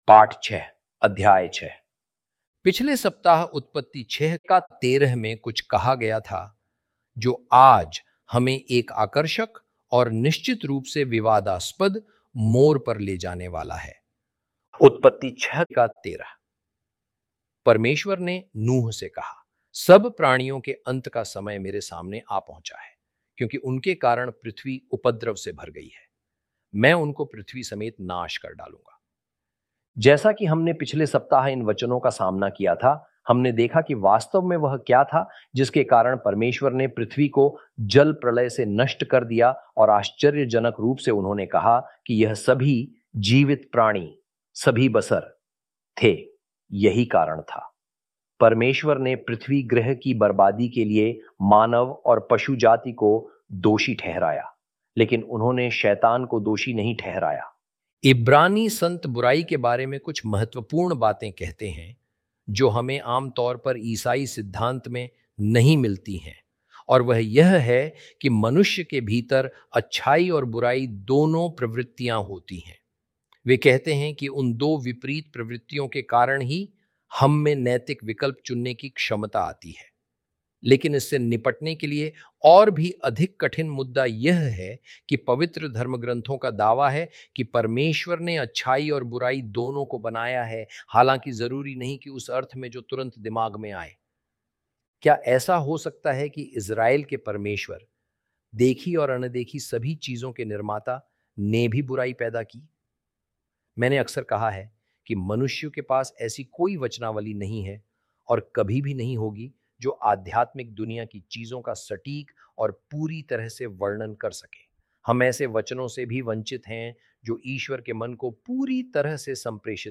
hi-audio-genesis-lesson-6-ch6.mp3